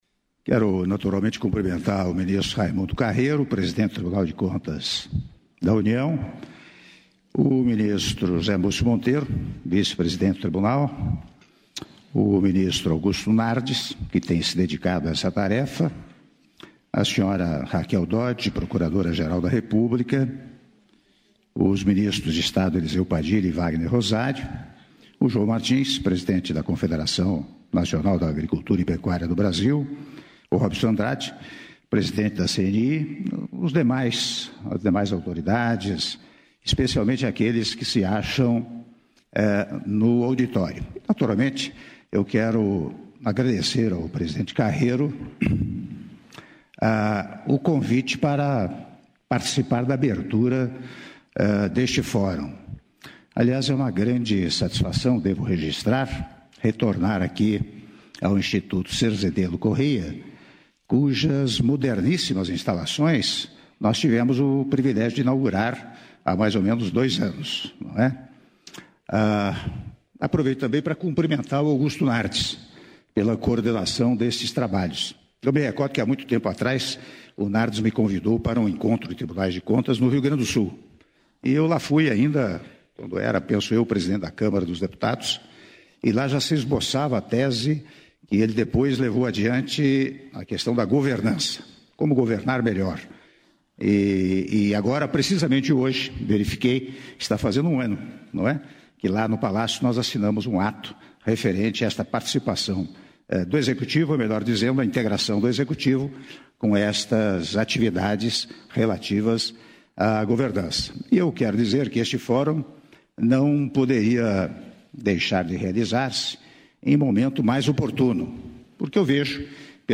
Áudio do Discurso do Presidente da República, Michel Temer, durante Cerimônia de abertura do II Fórum Nacional de Controle -Brasília/DF- (07min36s)